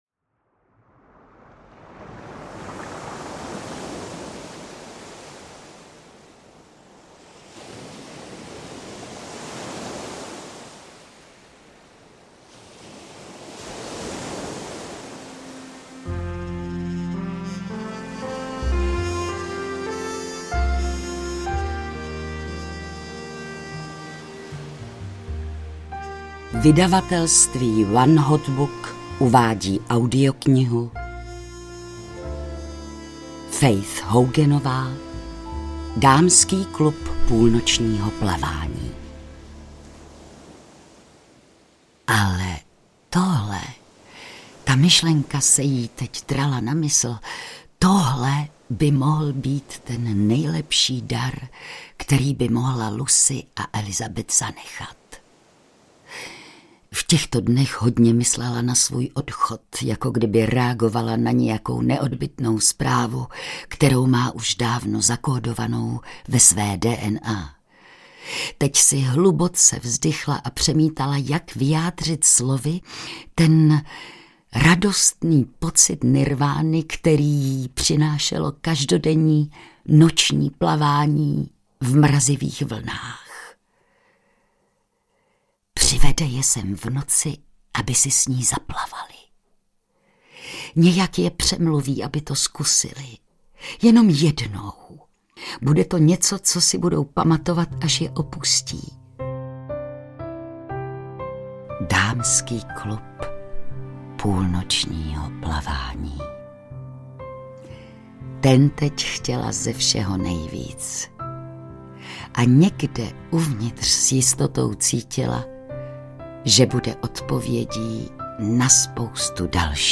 Dámský klub půlnočního plavání audiokniha
Ukázka z knihy
• InterpretVilma Cibulková